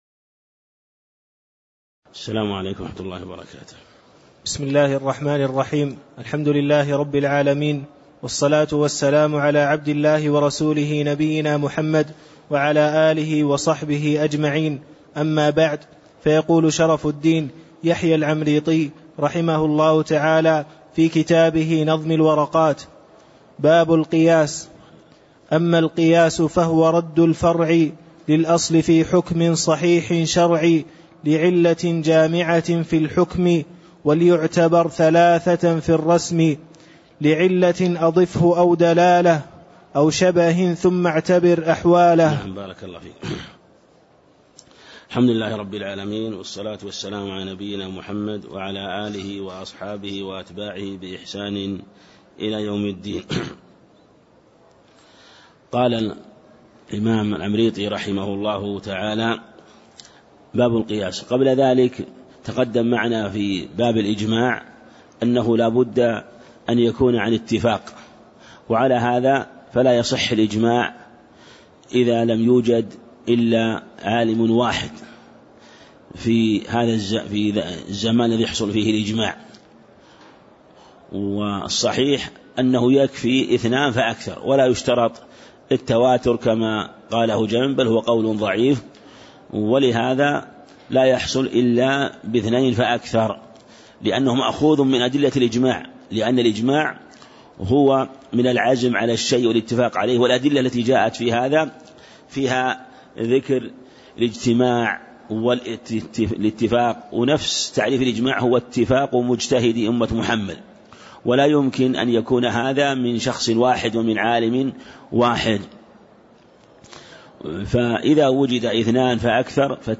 تاريخ النشر ١٧ شوال ١٤٣٦ هـ المكان: المسجد النبوي الشيخ